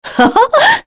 Scarlet-vox-Happy3.wav